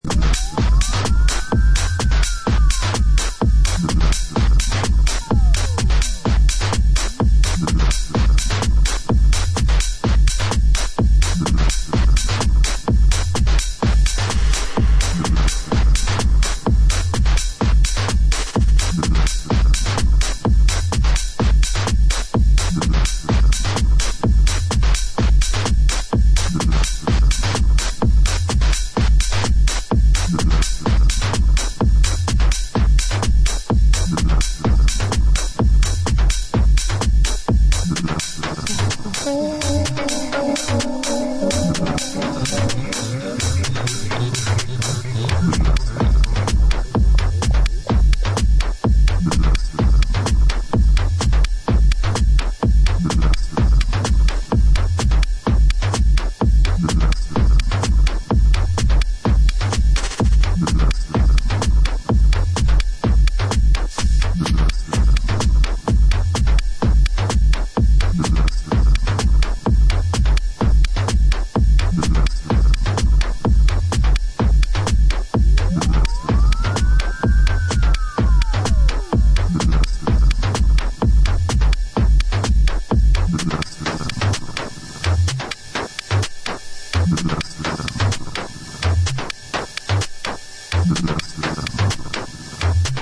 functional Berlin techno tracks